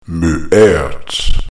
-ayrt?
[ö as in German]